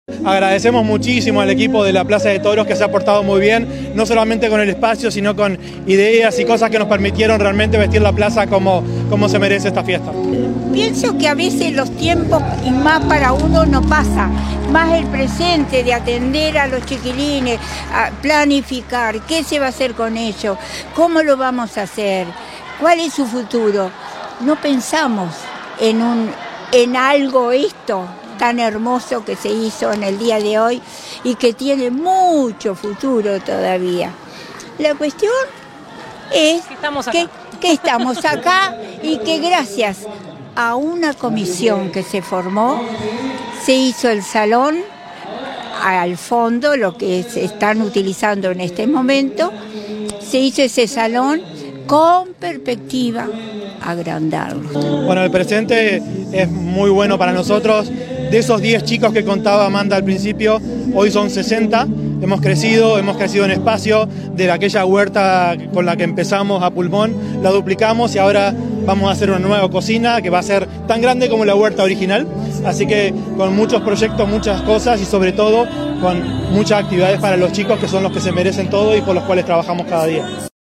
Se realizó en la Plaza de Toros y contó con integrantes de los distintos centros de atención a discapacitados del departamento.